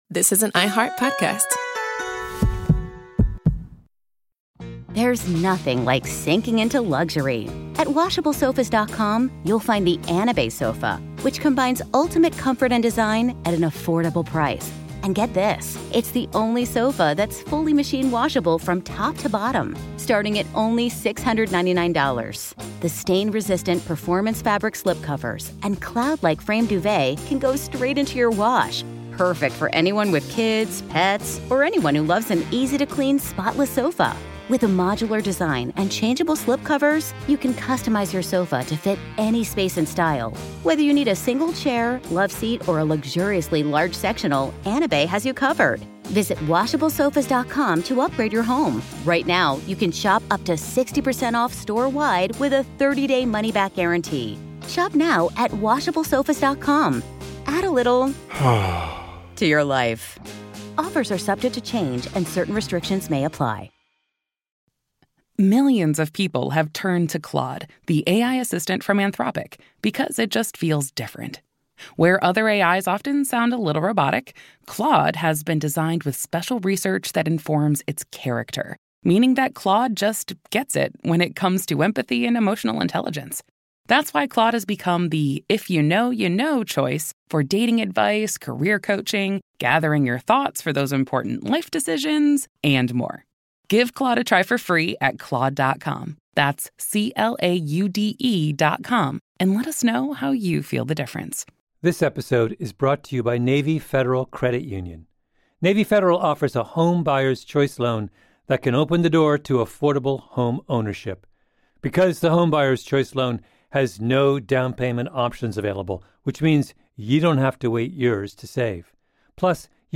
We'd like to thank the Bill of Rights Institute for allowing us access to this wonderful audio, originally a part of their Scholar Talks series on YouTube.